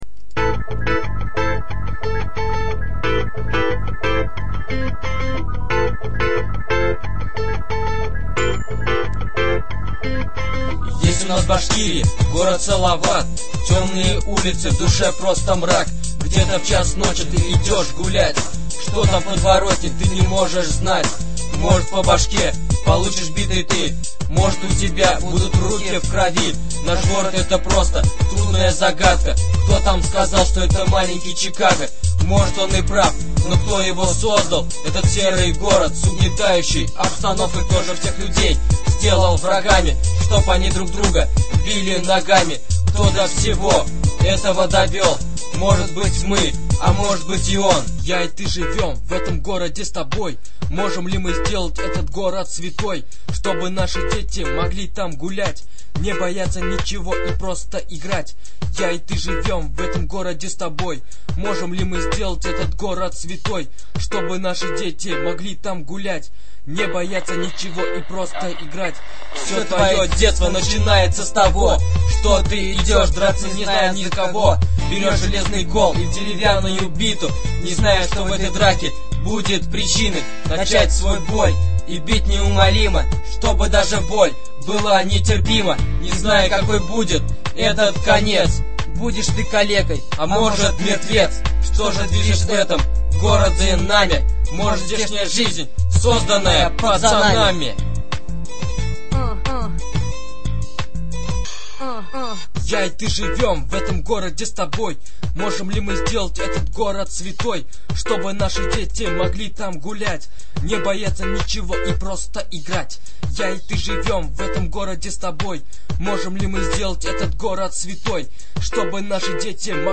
Жанр-рэп